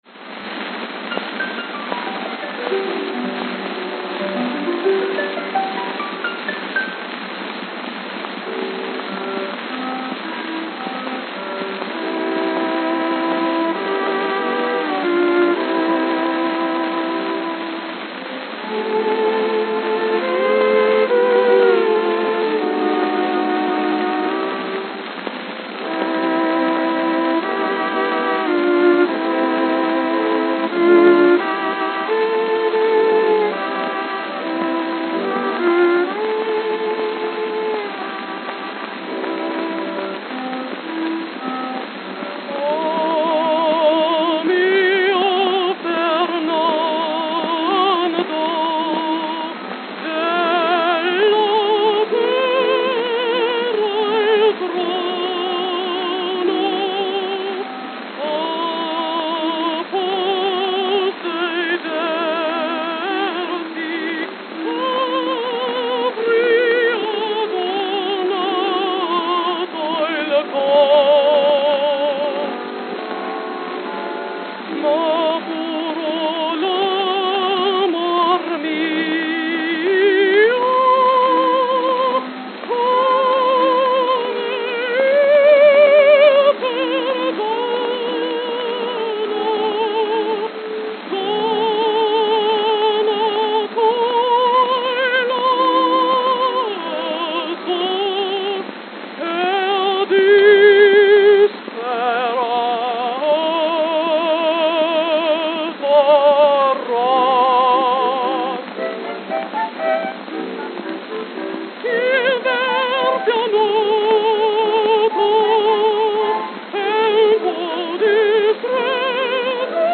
It was a prestigious tier featuring High Classical and Operatic selections with a base price of $2.00. It was analogous to Victor's Red Seal Label and Columbia's Symphony Series.
New York, New York New York, New York